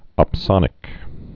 (ŏp-sŏnĭk)